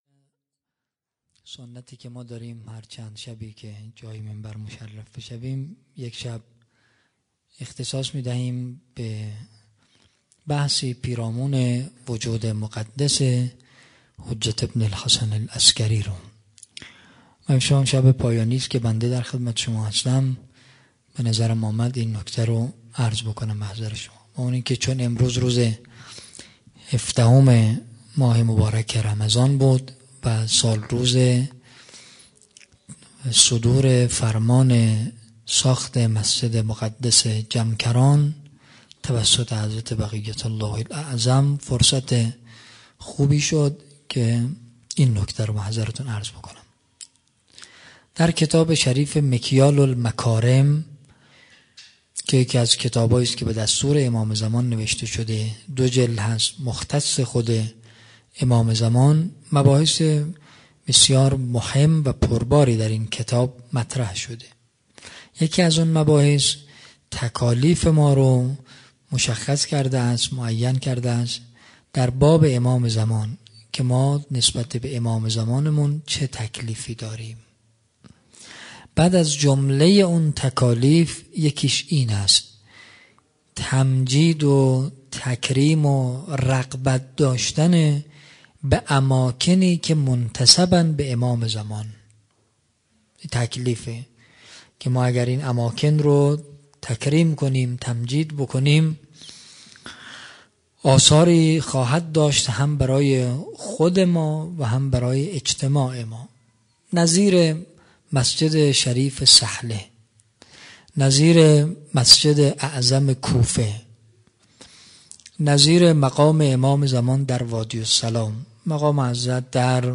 شب هیجدهم رمضان 96 - ریحانة النبی - سخنرانی